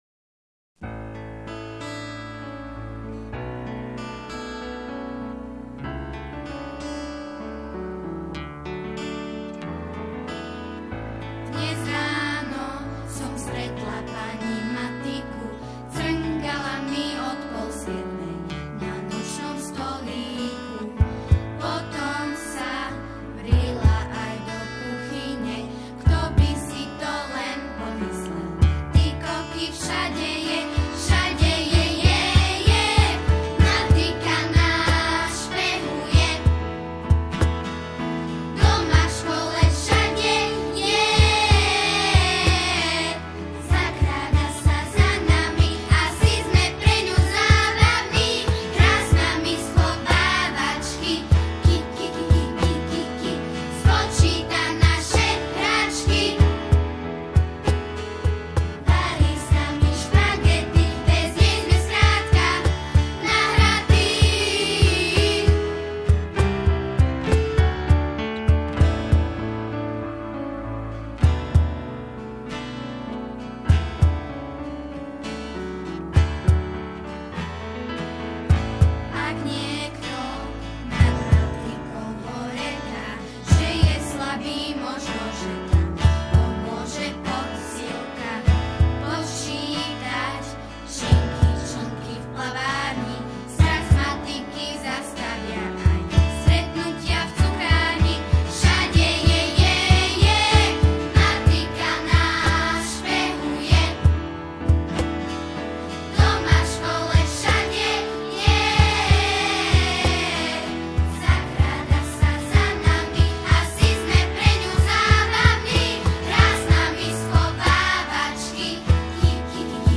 Zároveň si pesničky môžete stiahnuť ako mp3, niektoré aj v dvoch verziách, buď len samostatnú melódiu, alebo aj so spevom.
Špehovacia matika (Text, hudba: Bibiana Kľačková, Spev: žiaci SZŠ Dotyk Ružomberok)